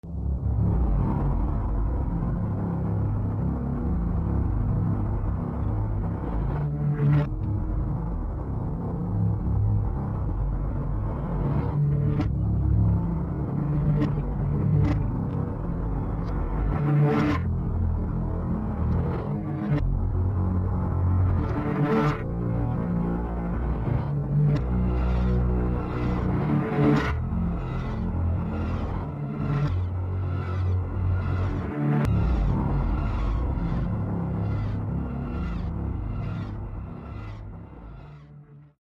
A diverse and distinct musical experience